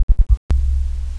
The first part of the signal is the original signal, a single 40 Hz sine wave pulse created using CoolEdit.
The resulting waveform shows the effect of the input and output sections of the AWE32 on the original 40 Hz signal.
There is very little difference between this and the second part of the recording - as it should be with all good amplifiers.
The close-miked method was used for the recording to minimize room effects (the microphone was placed directly in front of the port).
In interesting thing to note here is that the signal decays at about 52 Hz - the resonance frequency of the drivers used in this design.
The final part of the signal is the result I got using the same configuration above, but this time the Sound Level Meter was placed out in the room away from the 4th order bandpass system. The room in question has very bad standing wave problems, and the results show up in the recorded signal.